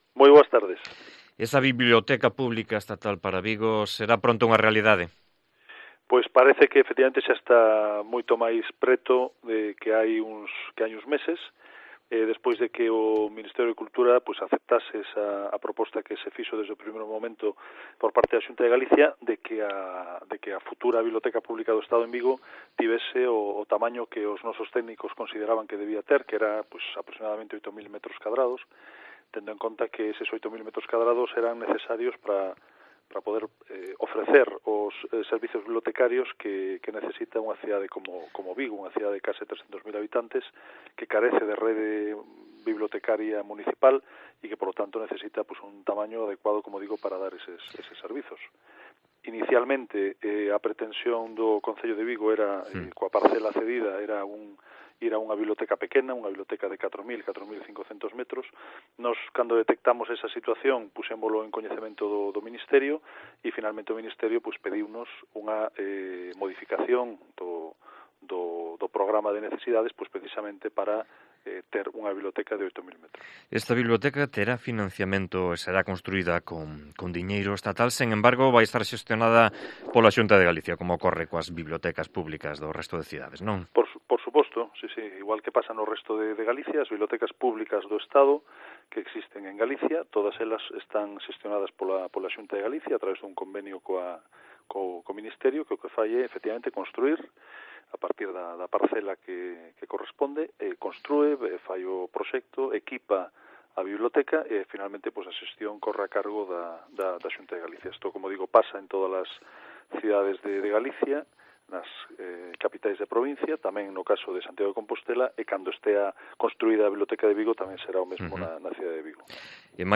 Entrevista con Anxo Lorenzo, Secretario Xeral de Cultura da Xunta